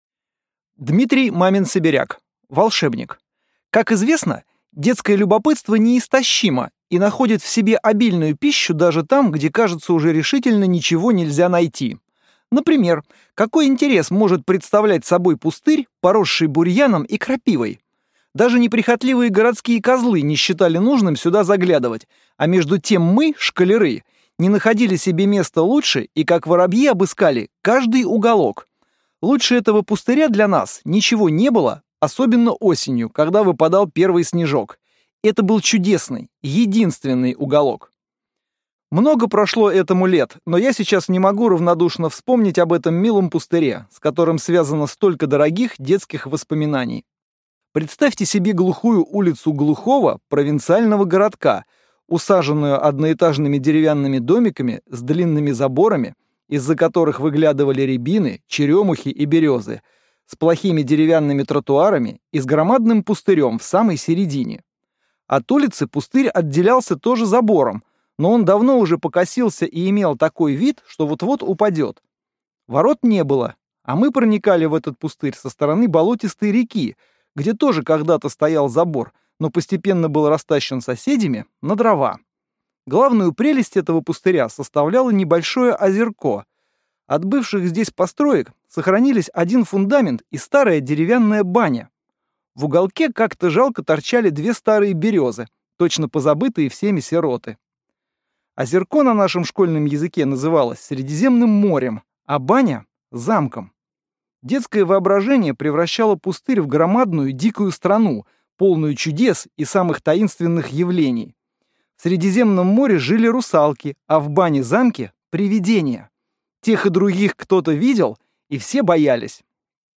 Аудиокнига Волшебник | Библиотека аудиокниг